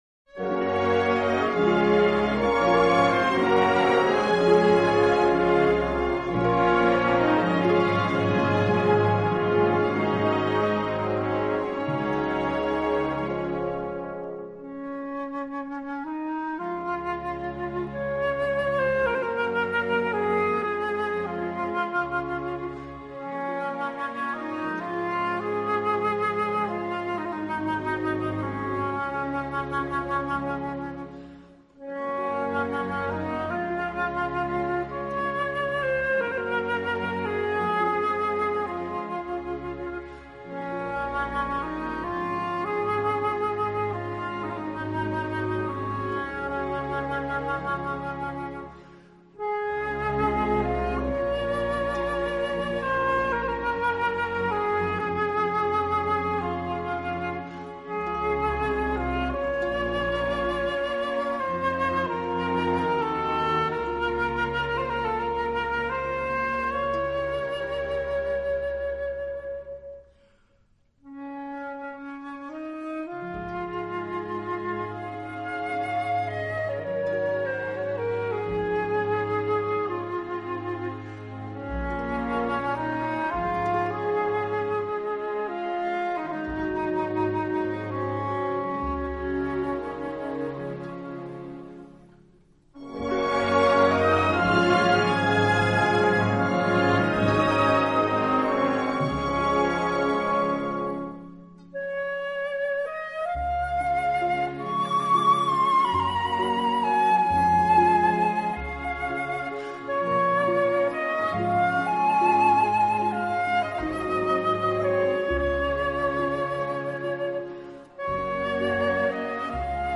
【新世纪长笛】